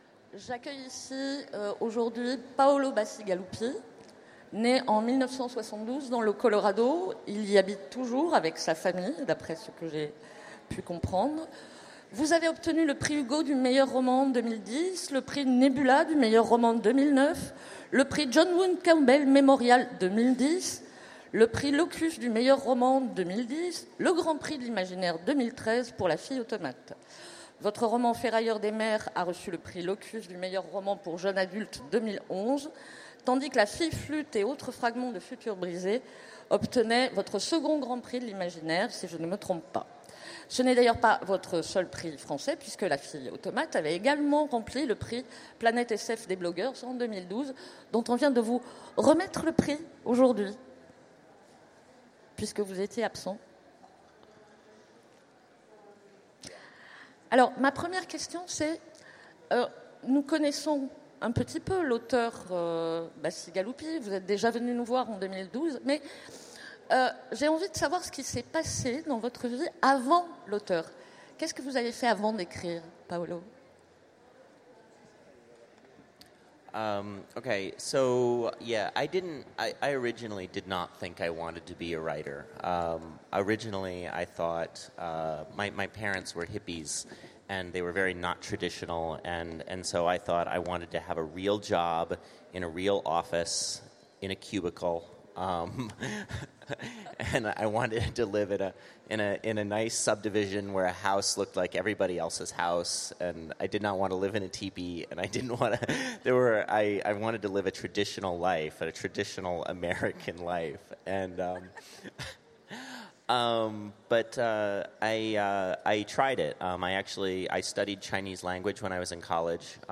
Utopiales 2016 : Rencontre avec Paolo Bacigalupi
- le 31/10/2017 Partager Commenter Utopiales 2016 : Rencontre avec Paolo Bacigalupi Télécharger le MP3 à lire aussi Paolo Bacigalupi Genres / Mots-clés Rencontre avec un auteur Conférence Partager cet article